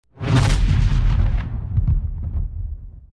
starprobelaunch.ogg